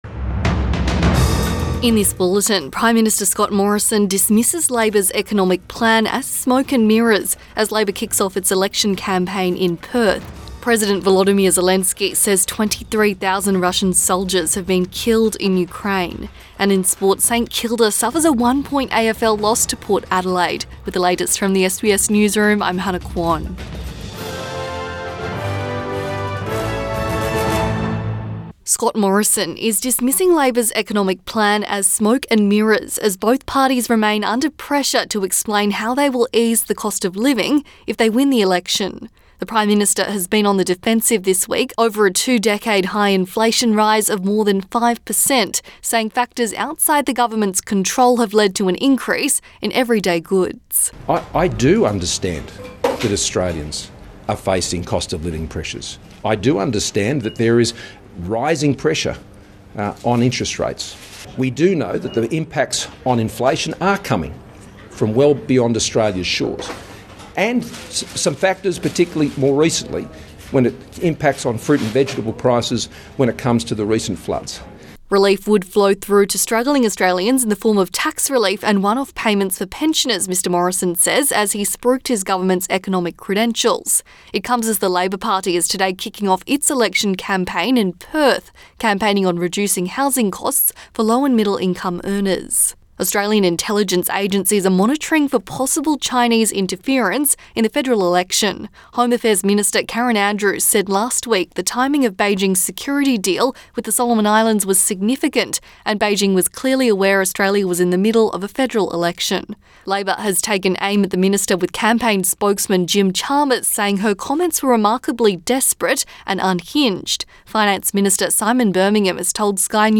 Midday bulletin 1 May 2022